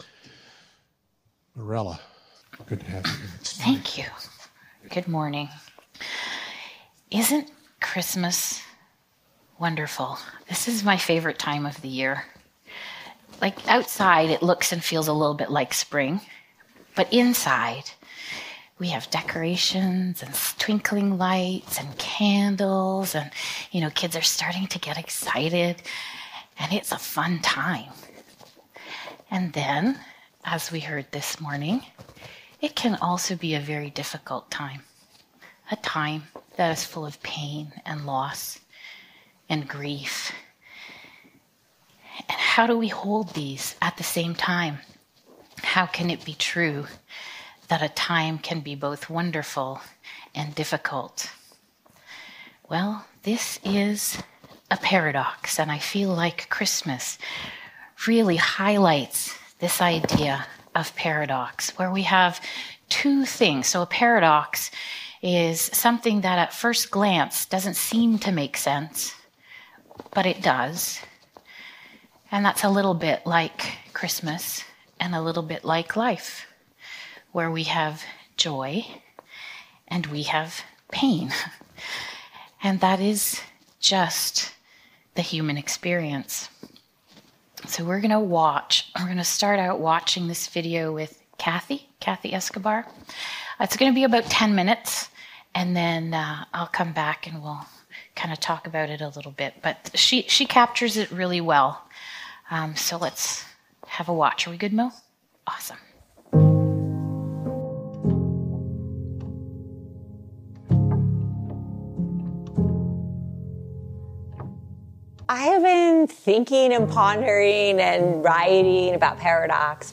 O Come O Come Emmanuel Service Type: Sunday Morning Living in Paradox